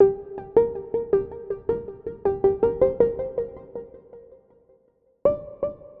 Ambient pads and ethereal keys.
BeautifulArp.mp3